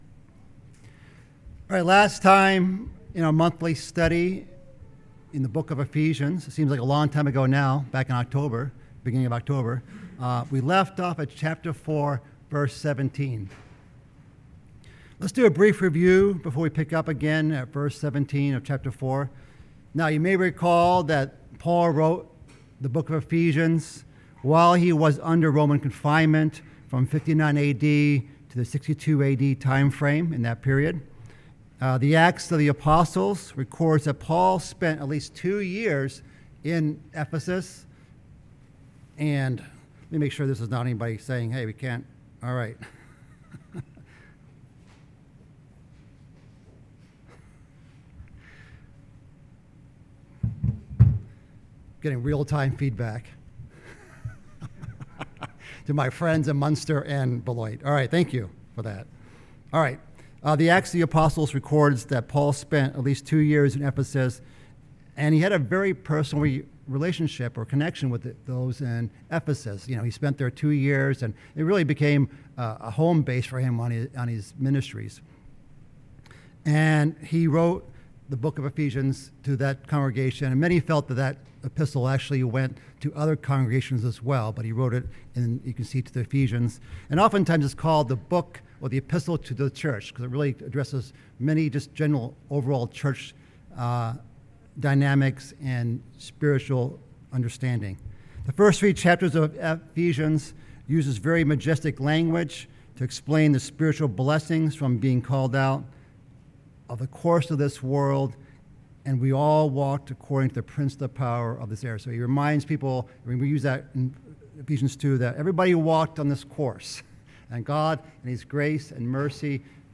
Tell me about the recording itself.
This study, given in Chicago and virtually given in NW Indiana and Beloit Wisconsin, covers verses 17-32 of Ephesians 4